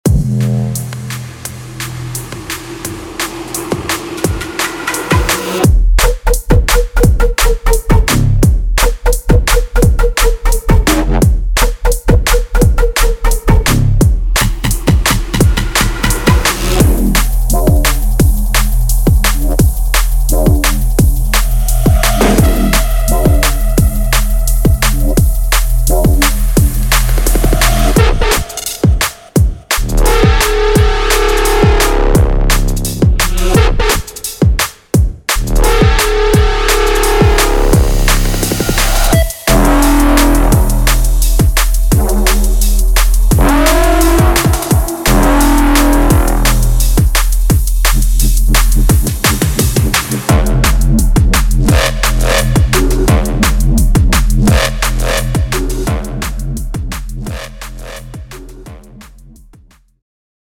Drum And Bass